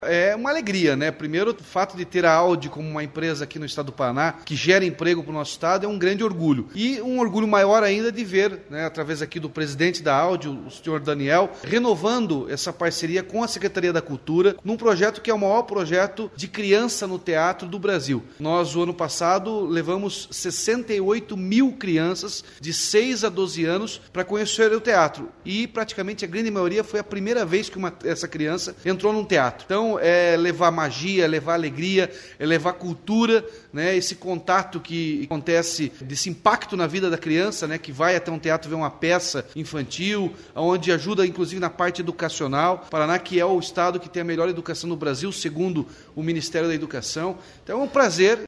Sonora do governador Ratinho Junior sobre a parceria com a Audi para levar teatro a crianças e reformar o Guaíra